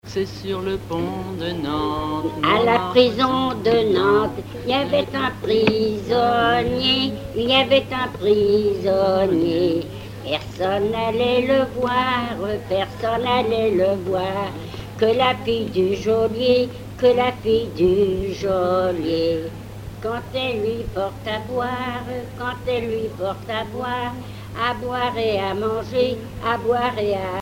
Genre laisse
Chansons et commentaires
Pièce musicale inédite